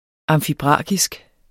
Udtale [ ɑmfiˈbʁɑkisg ]